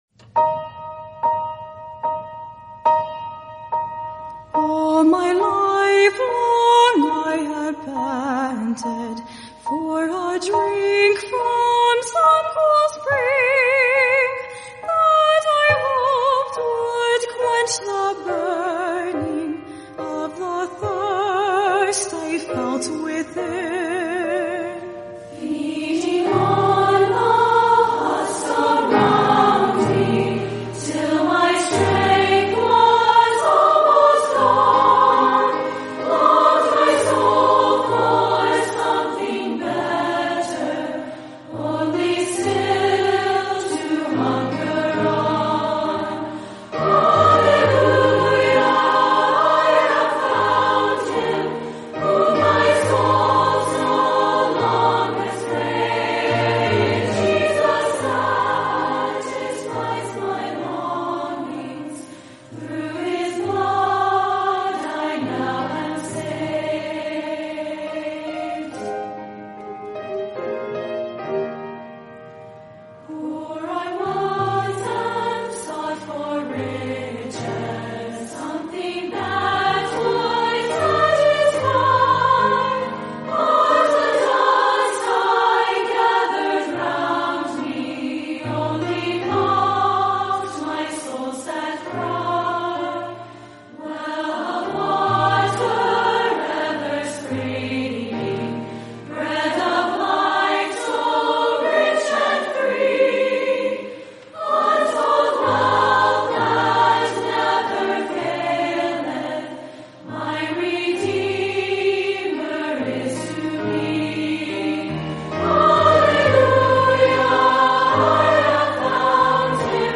“Satisfied (Hallelujah! I Have Found Him!)" Performed by Wilds Summer Staff Choir.